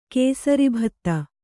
♪ kēsari bhatta